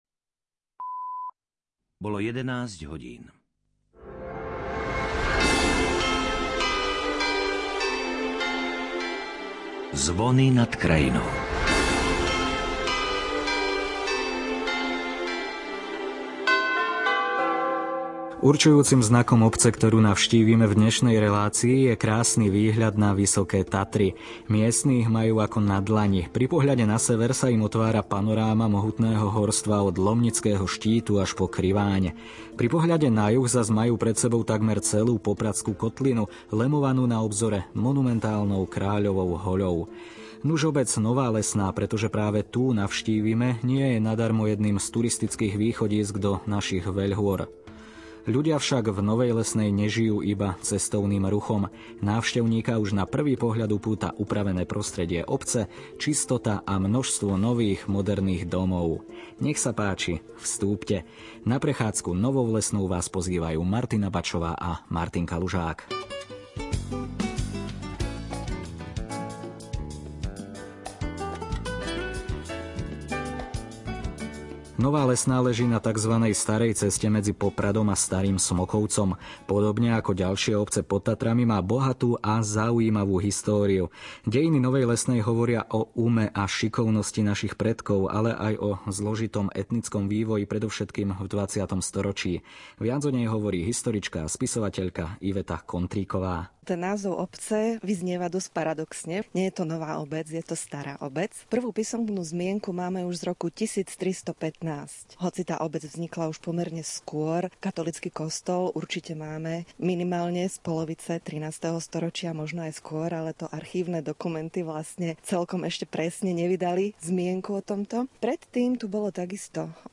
O tom že naša obec s najkrajším výhľadom na Vysoké Tatry má čo ponúknuť návštevníkom z celého Slovenska, sa poslucháči presvedčia už nasledujúcu nedeľu. Dvojica redaktorov na tento deň pripravuje košické vydanie relácie Zvony nad krajinou – zvukový miestopis Slovenska, resp. rozhlasový dokument o živote v obciach Slovenska, ich minulosti, súčasnosti a plánoch do budúcnosti. Redaktori navštívili kultúrno-historické miesta v obci (sypanec, r.k.kostol Zvestovania Pána), Sinajskú kaplnku, slnečné hodiny , materskú škôlku, farskú budovu a urobili plánované aj náhodné rozhovory (odchyt včelieho roja) s obyvateľmi našej obce.